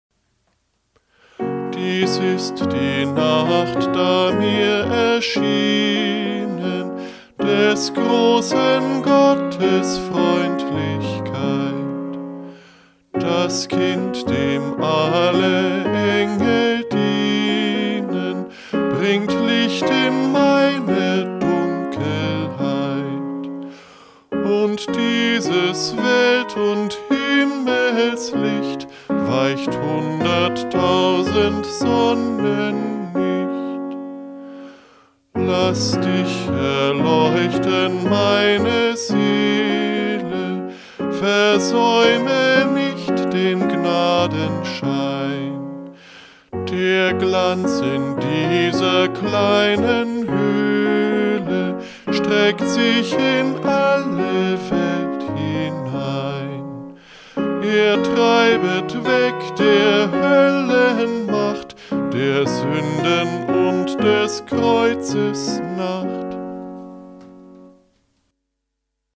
Liedvortrag